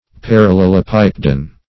Parallelopipedon \Par`al*lel`o*pip"e*don\, n. [NL.]